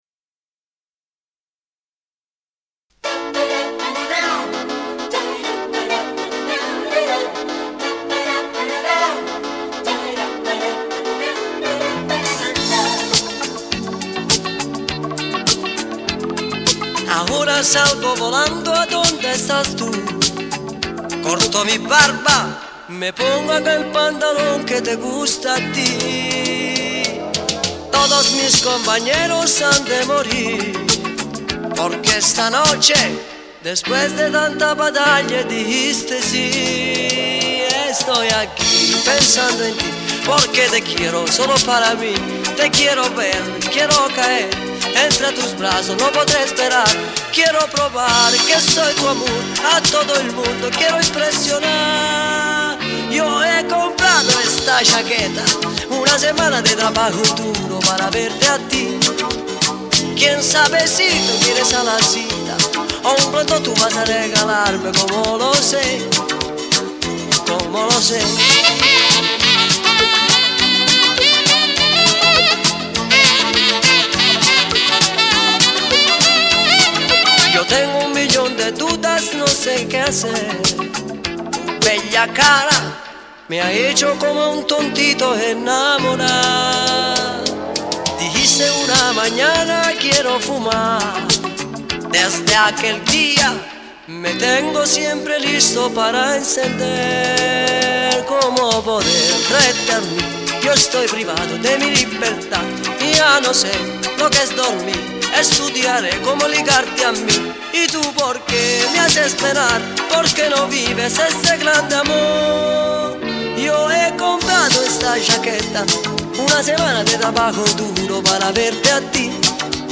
Coro
Batteria
Chitarra
Basso
Pianoforte, Sintetizzatori e Tastiere